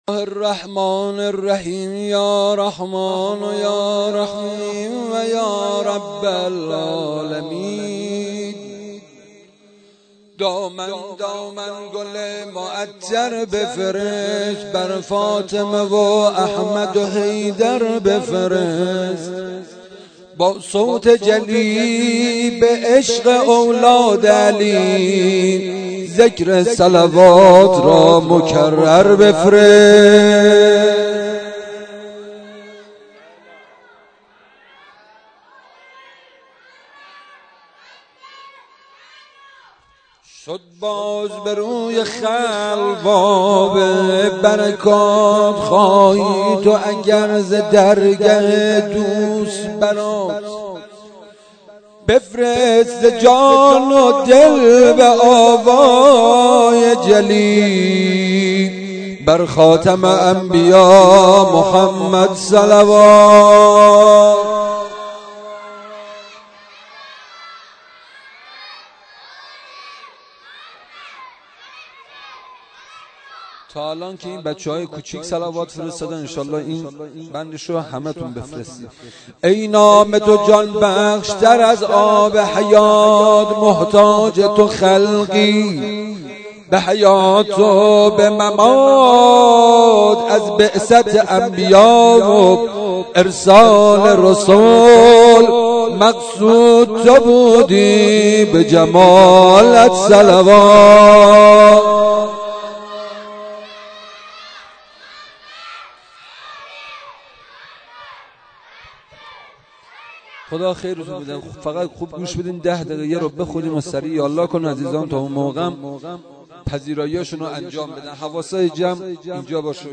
مولودی خوانی
مراسم جشن سالروز میلاد پیامبر اکرم(ص) و امام جعفر صادق(ع).mp3